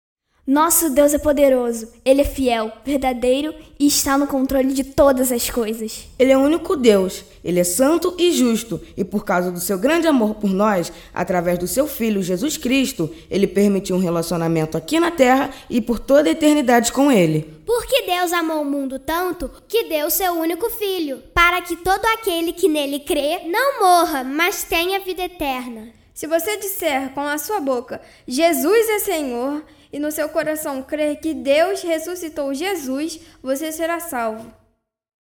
05 - Narração 03